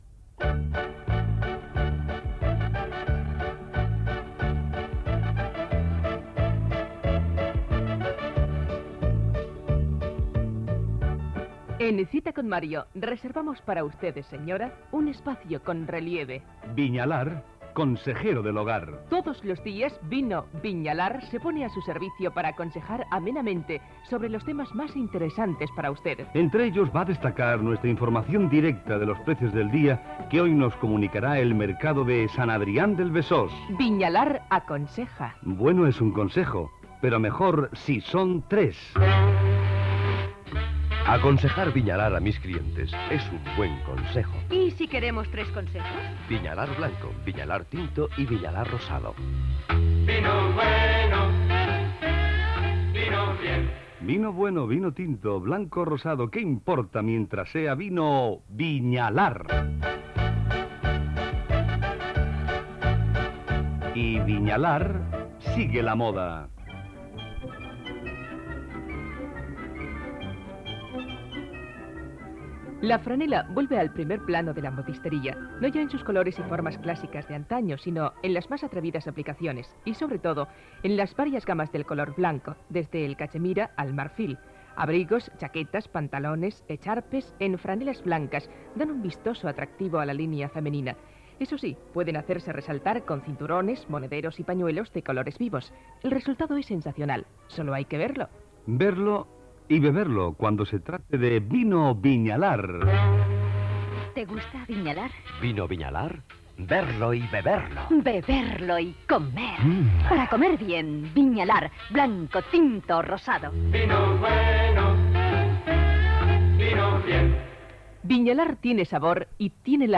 Espai publicitari de Vinos Viñalar. Consells sobre la franel·la, tema musical, consells domèstics, curiositat sobre un anell d'un Maharajà, preus del Mercat de Sant Adrià de Besòs, tema musical Gènere radiofònic Entreteniment
Procedent d'una cinta magnetofònica de bobina oberta de l'estudi Cima S.A. de Madrid